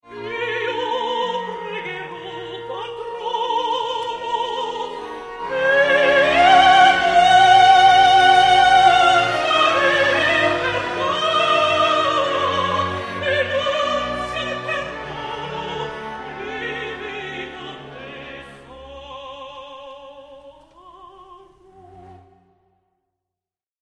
mezzosoprano